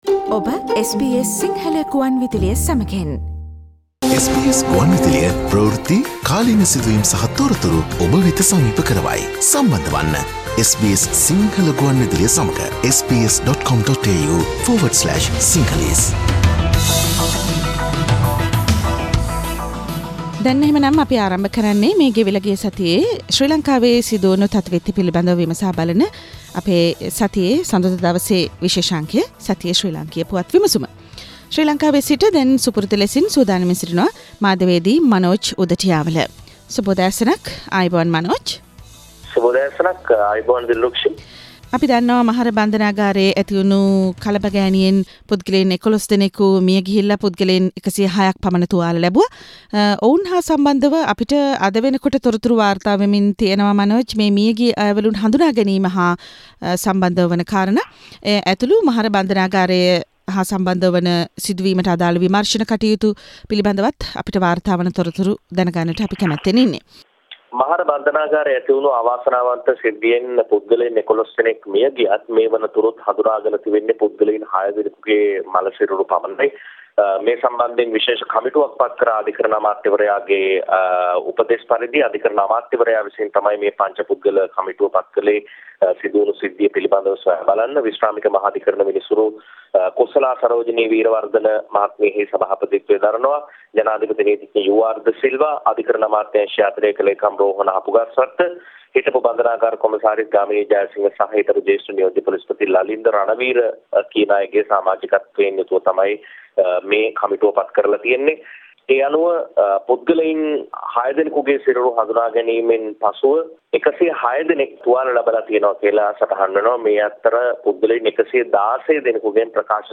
SBS Sinhala radio brings you a comprehensive wrap up of the highlighted news from Sri Lanka with Journalist